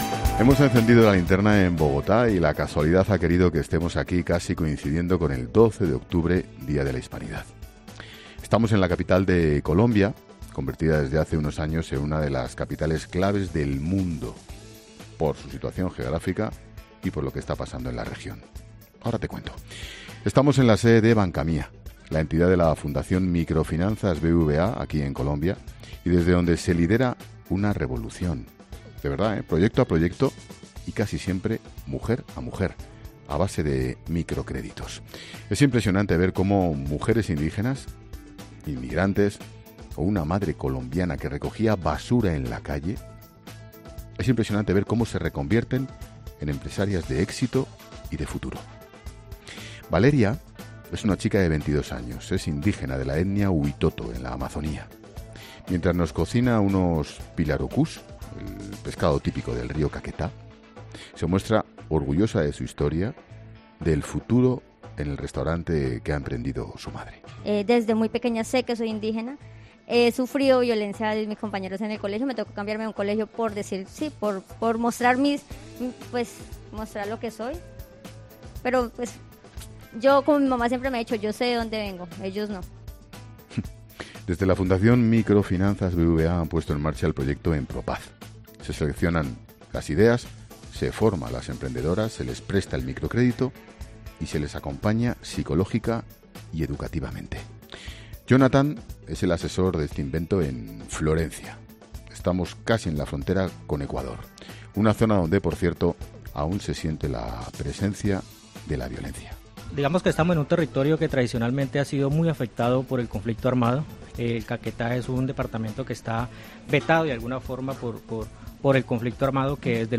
Ángel Expósito, desde Bogotá: "El dolor de esta gente te encoge el alma"
Monólogo de Expósito
El director de 'La Linterna', Ángel Expósito, reflexiona sobre el Día de la Hispanidad desde Bogotá